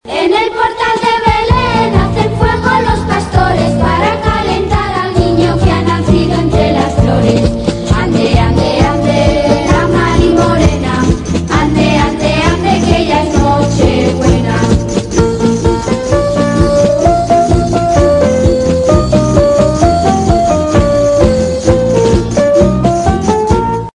villancico de Navidad